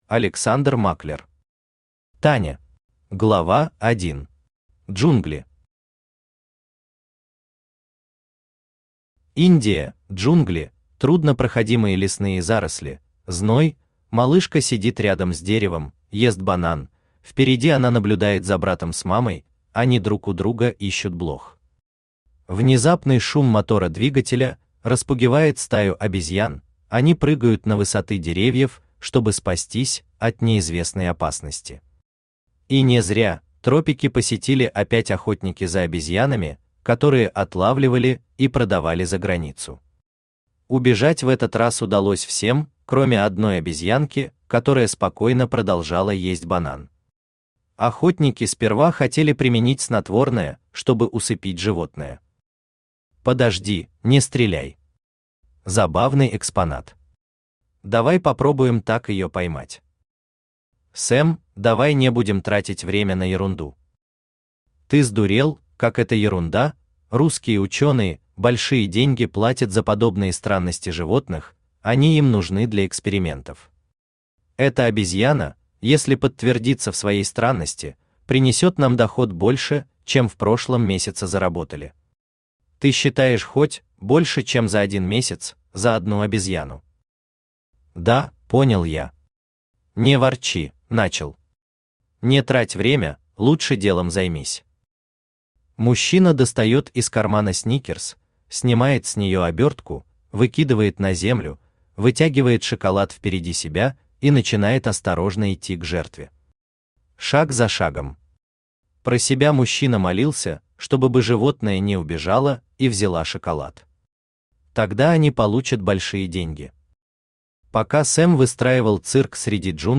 Аудиокнига Таня | Библиотека аудиокниг
Aудиокнига Таня Автор Александр Германович Маклер Читает аудиокнигу Авточтец ЛитРес.